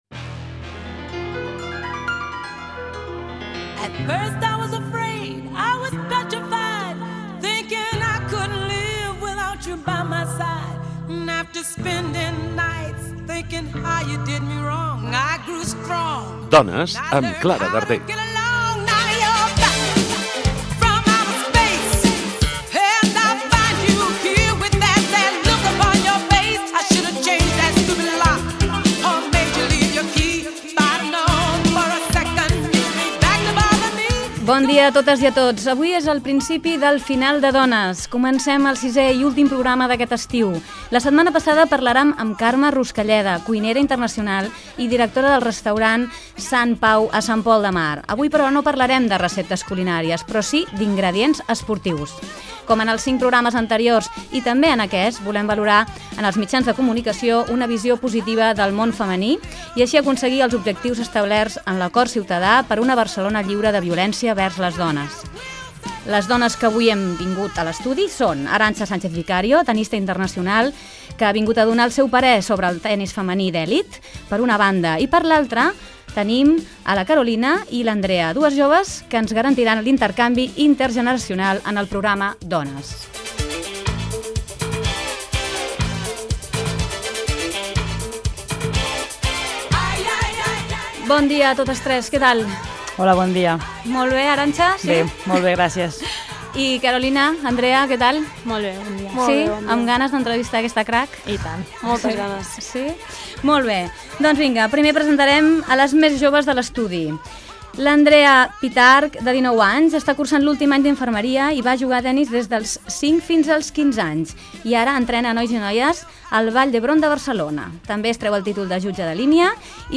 Careta del programa, presentació i fragment d'una estrevista a la tennista Arantxa Sánchez Vicario.
Entreteniment